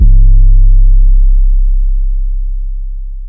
808 [most on jackboys].wav